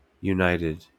wymowa amerykańska?/i